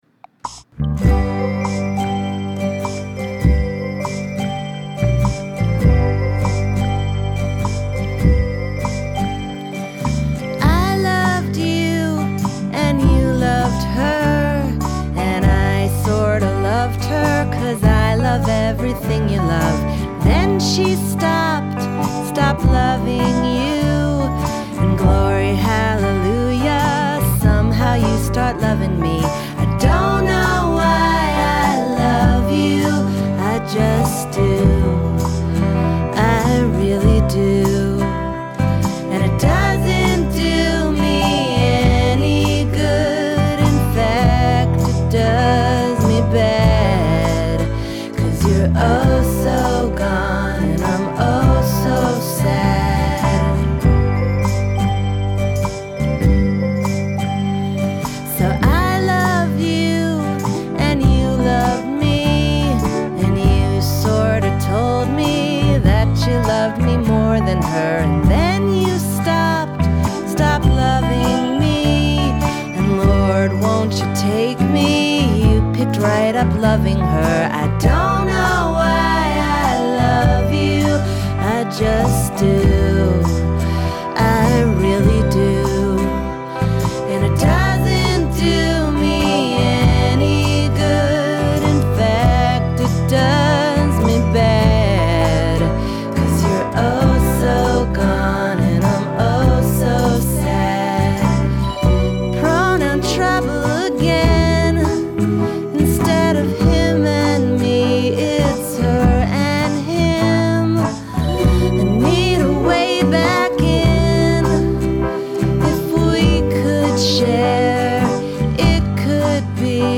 happier, poppier version.